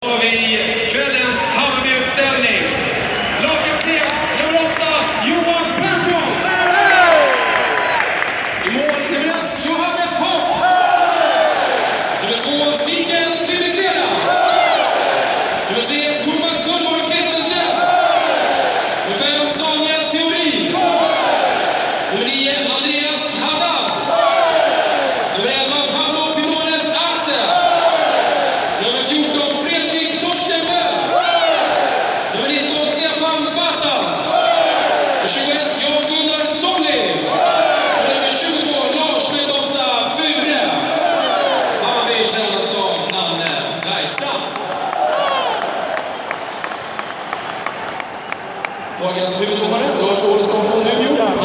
@ nya söderstadion